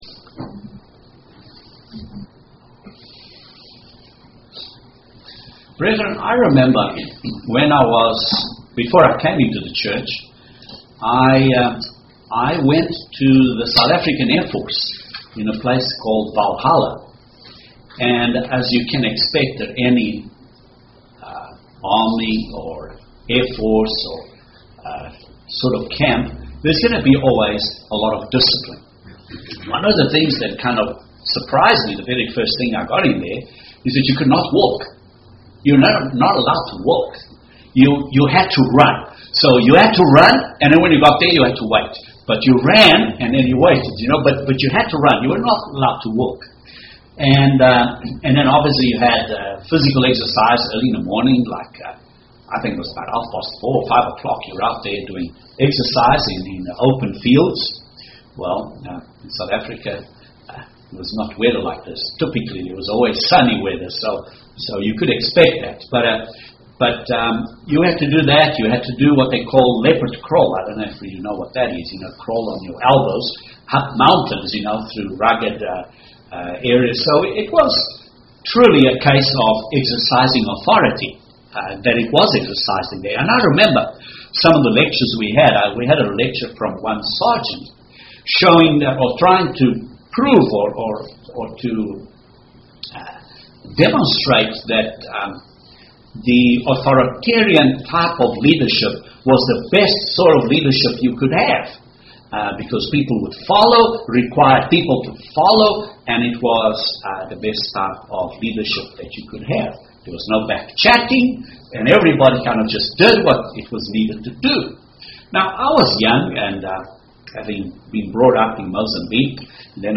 Using three simple words, which describe Christ, namely Lord, Master and Brother, this sermon expounds 3 principles of Christ-like leadership.